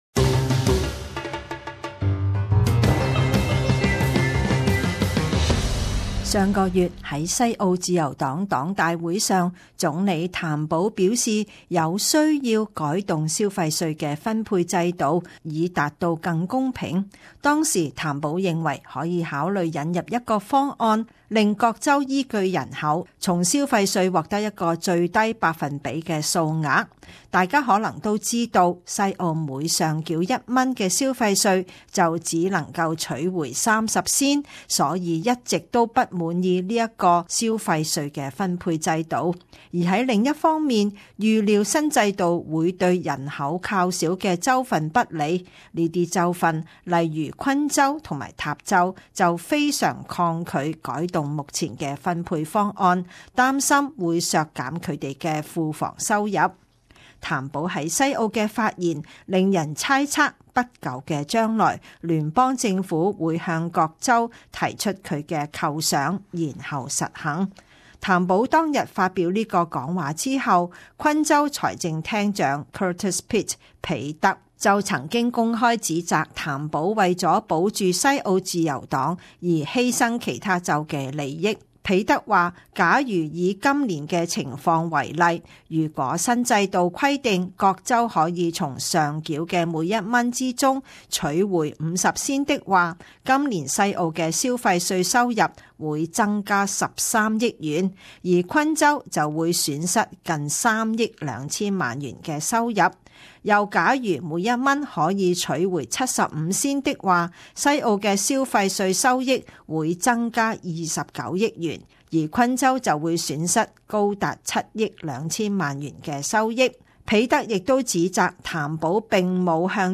【时事报导】 消费税改革短期内难以实施